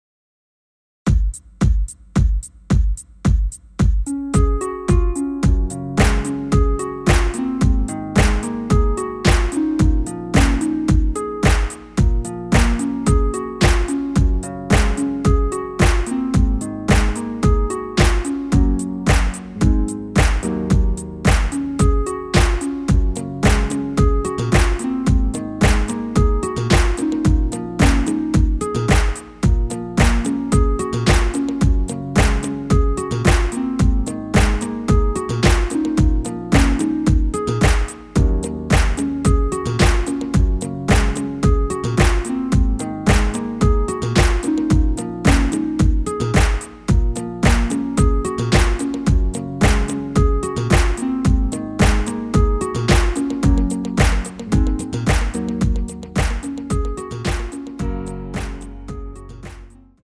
Db
앞부분30초, 뒷부분30초씩 편집해서 올려 드리고 있습니다.
중간에 음이 끈어지고 다시 나오는 이유는
곡명 옆 (-1)은 반음 내림, (+1)은 반음 올림 입니다.